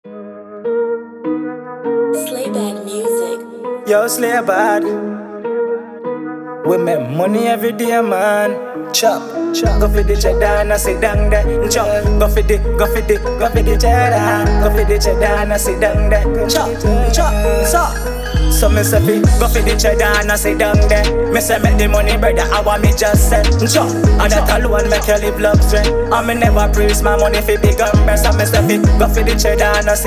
Genre: Reggae Subgenre: Dancehall/Reggae